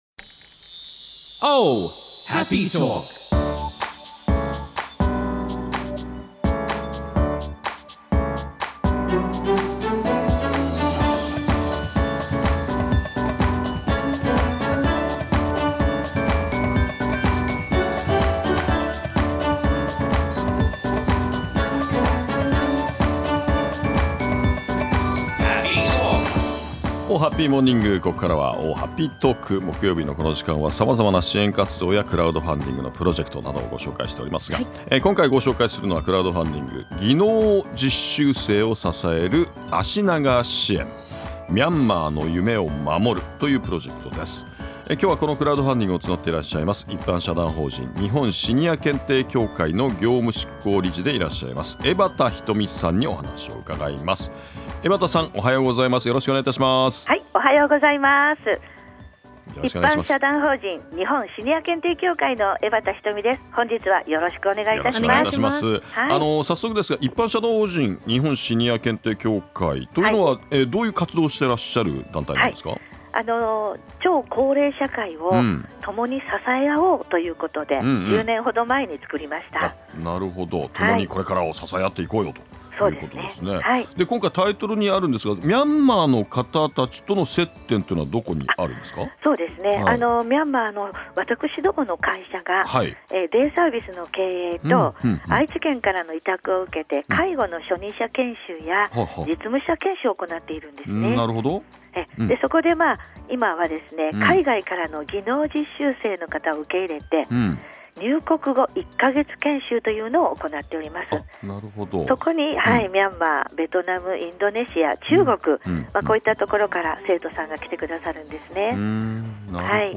★実際に放送されました生の音源になります♪(一部のみ)